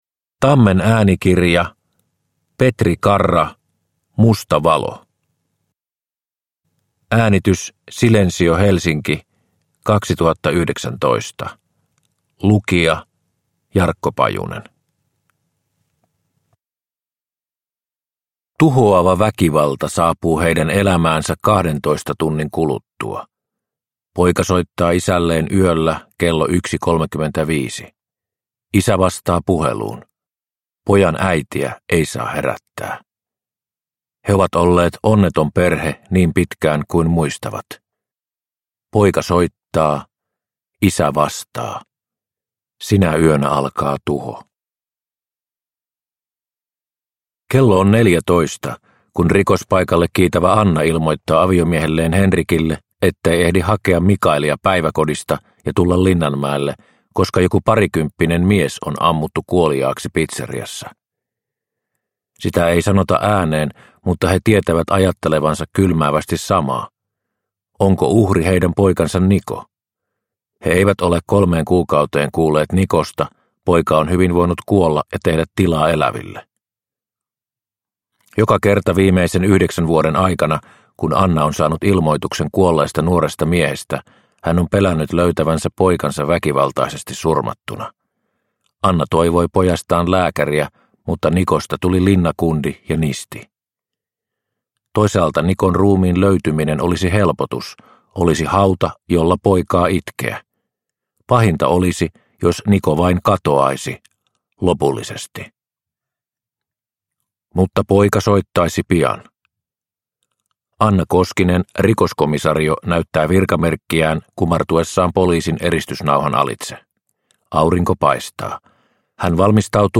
Musta valo – Ljudbok – Laddas ner